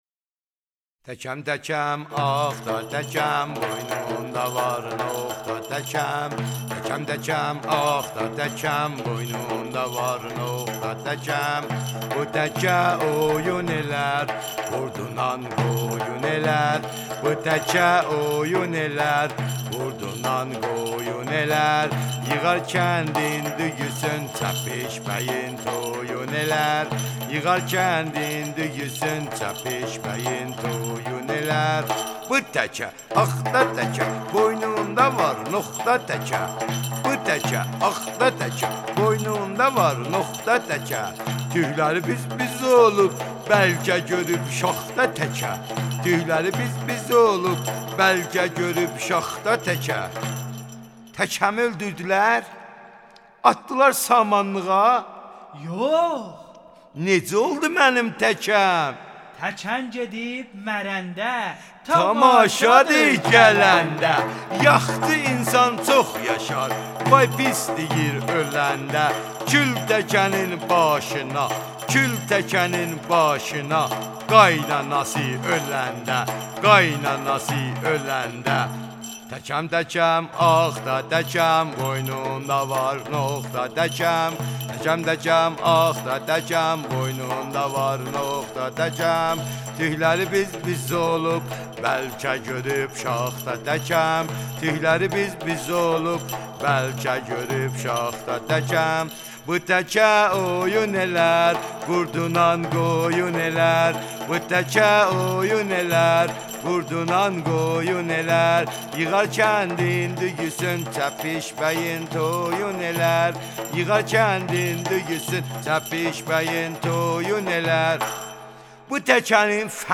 تکم‌چی به وسیله این چوب، تکم را به رقص درآورده و ریتم و ضربی هماهنگ از برخورد تکم بر صفحه چوبی ایجاد می‌کند.